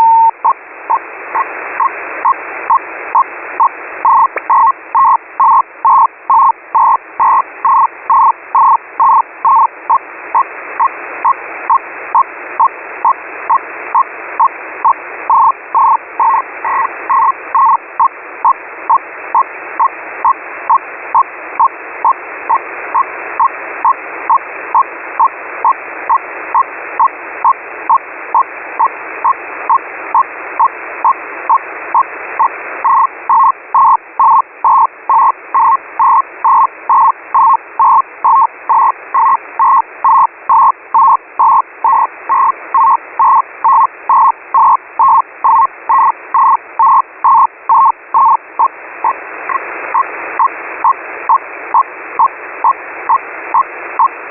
SITOR-A
Начало » Записи » Радиоcигналы классифицированные
Baudrate: 100 Shift: 170 Center: 1000